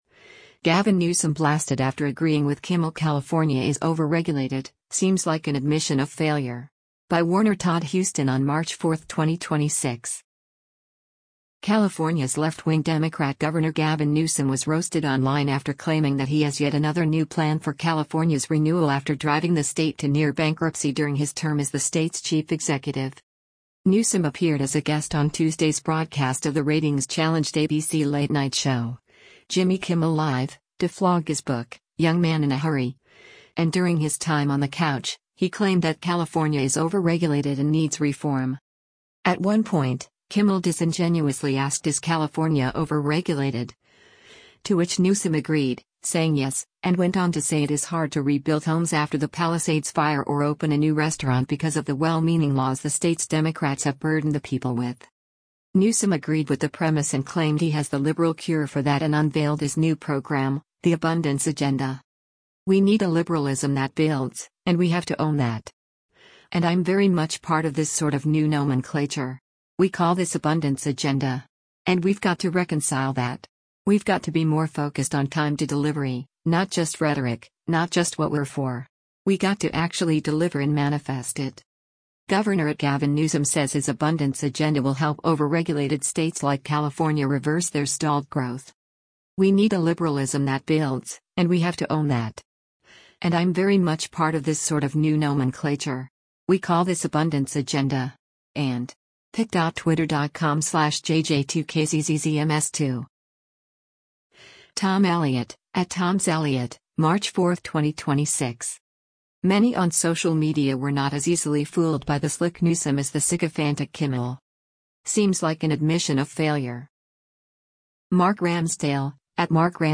Newsom appeared as a guest on Tuesday’s broadcast of the ratings-challenged ABC late-night show, Jimmy Kimmel Live!, to flog his book, Young Man in a Hurry, and during his time on the couch, he claimed that California is “overregulated” and needs reform.